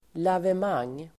Ladda ner uttalet
Folkets service: lavemang lavemang substantiv, enema Uttal: [lavem'ang:] Böjningar: lavemanget, lavemang, lavemangen Definition: sköljning av tarmen för att framkalla avföring enema substantiv, lavemang [medicinskt]